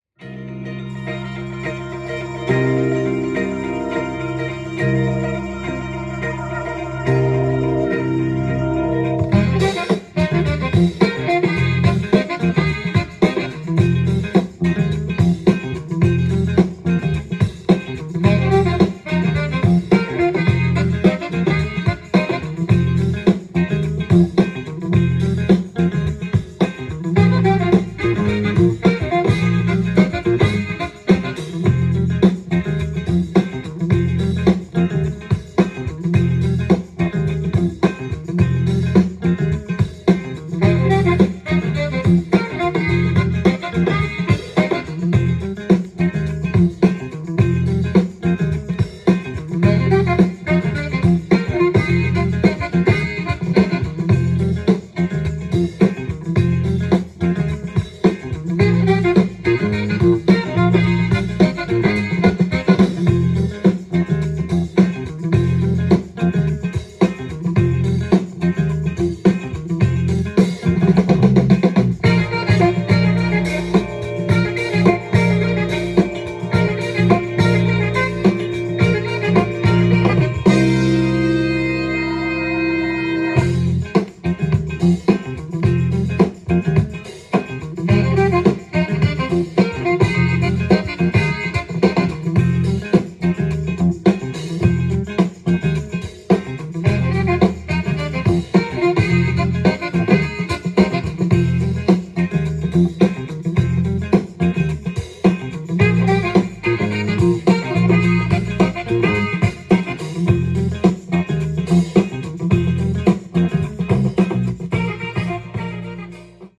ジャンル：FUNK
店頭で録音した音源の為、多少の外部音や音質の悪さはございますが、サンプルとしてご視聴ください。